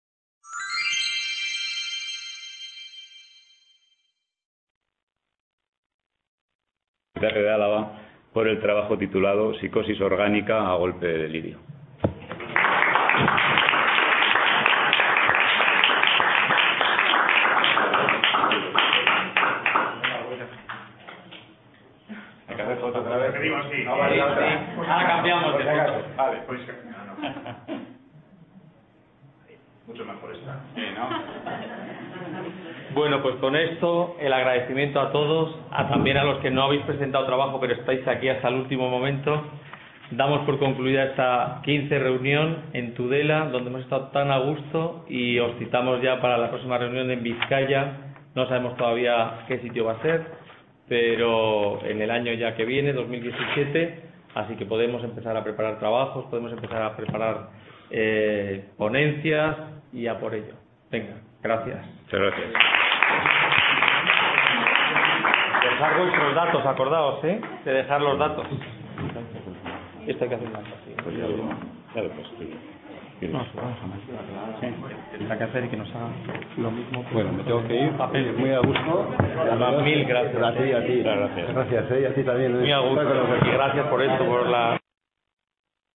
Entrega de premios.
Enfermedad Mental: Integración Familiar y Laboral - XV Reunión Anual de la Sociedad Vasco Navarra de Psiquiatría